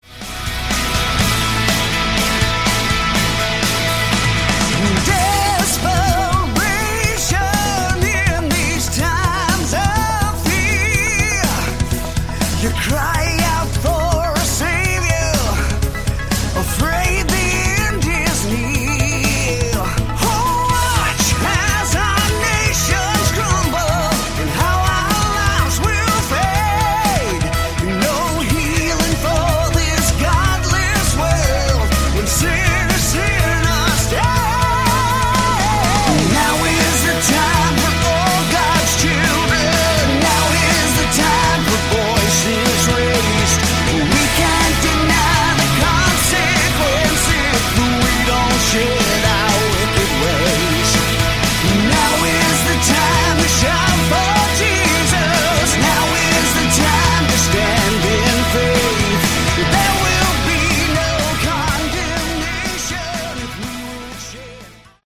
Category: Hard Rock
lead vocals, keyboards
guitars, vocals
drums
bass